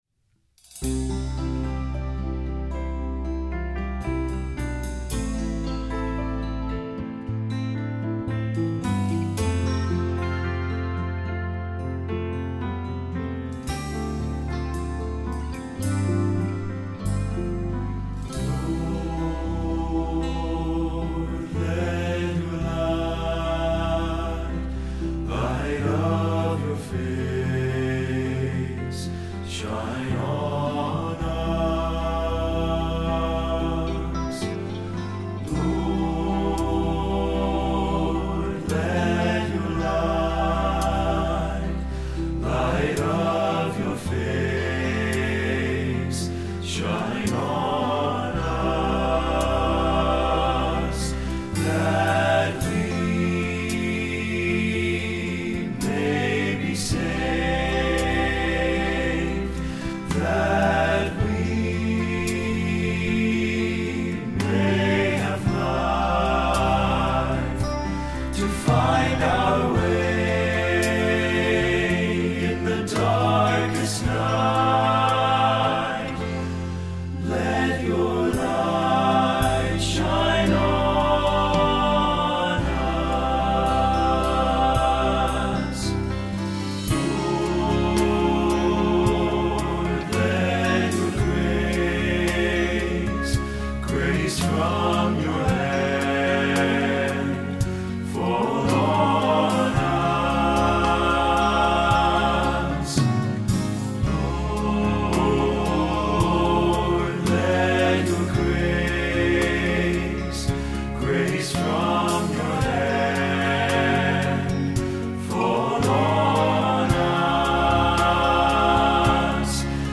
Choral Church Male Chorus
TTBB